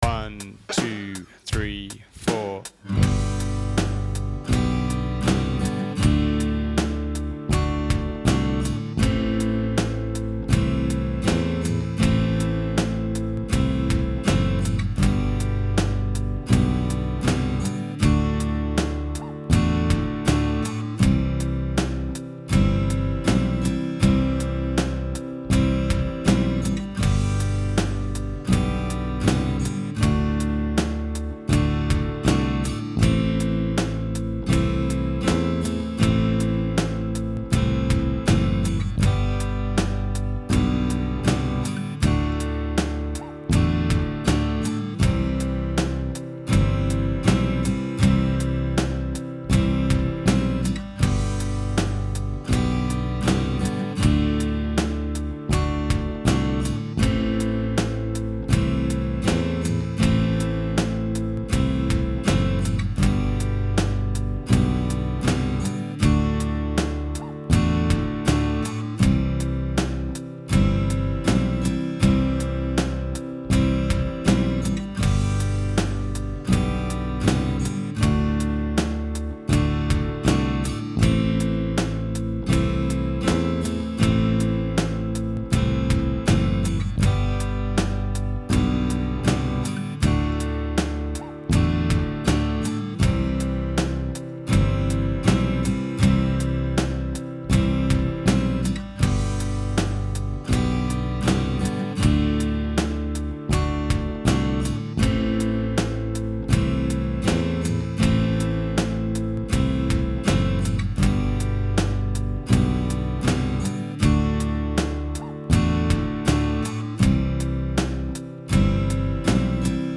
Tapping On Gods Gate Backing Track | Download